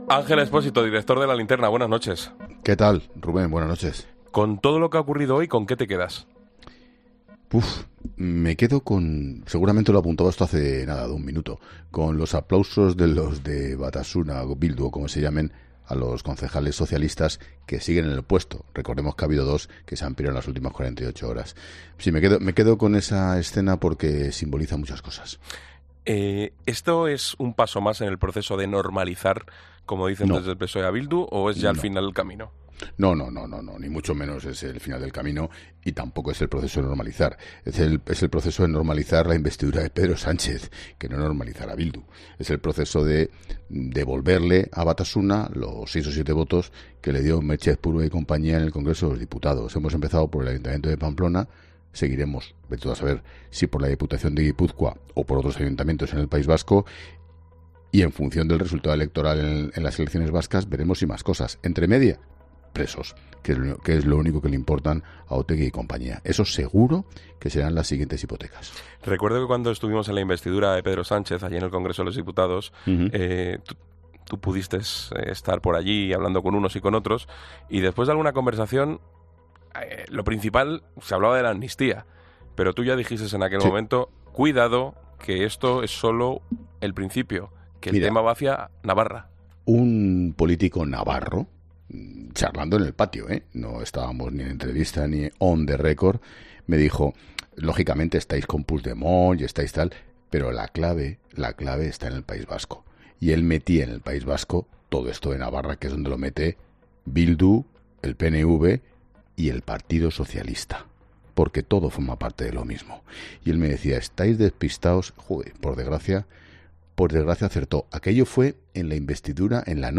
El director de La Linterna, Ángel Expósito, revelaba este jueves en La Linterna la predicción que le hizo un político navarro del que no ha querido desvelar su nombre sobre lo que pasaría en el Ayuntamiento de Pamplona, donde ha prosperado una moción de censura de Bildu con el apoyo de los concejales socialistas.